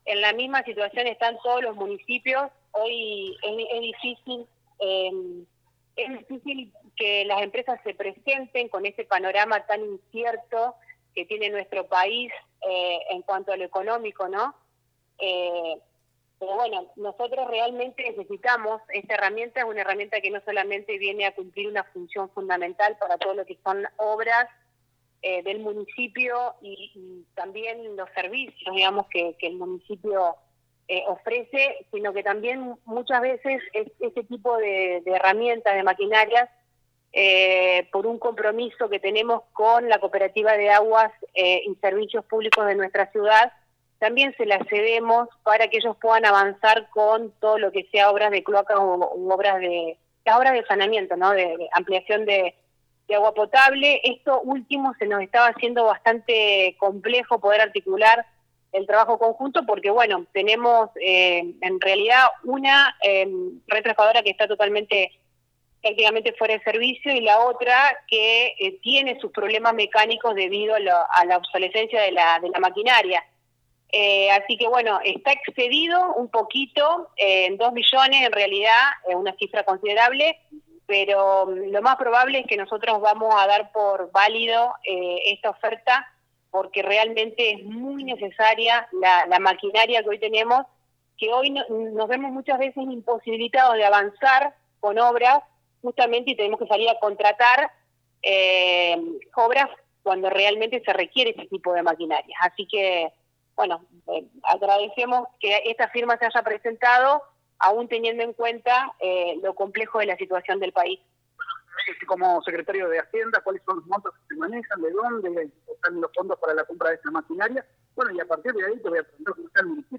Luego de la apertura de sobre hablamos con la Intendente Alejandra Dupouy y con el Secretario de Hacienda Miguel Andrada.